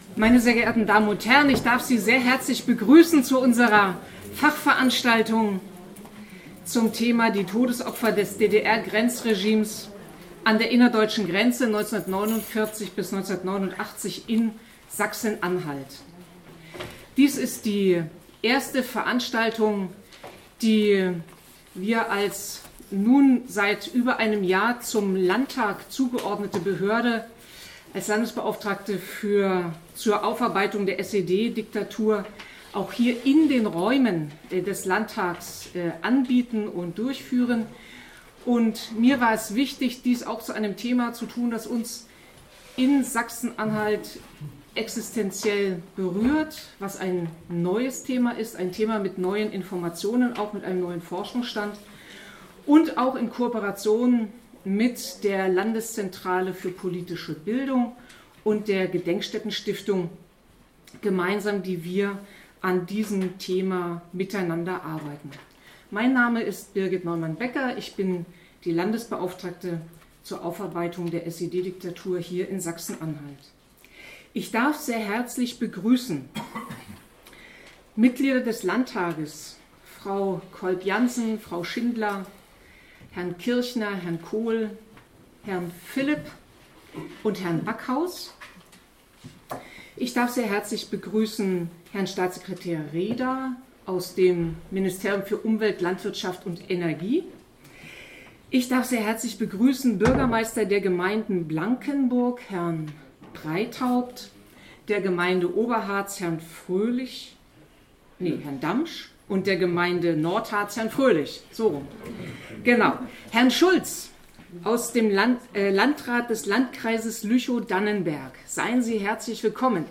Dokumentation der Fachveranstaltung im Magdeburger Landtag 28.2.2018: „Die Todesopfer des DDR-Grenzregimes an der innerdeutschen Grenze 1949–1989 in Sachsen-Anhalt“
Begrüßung Birgit Neumann-Becker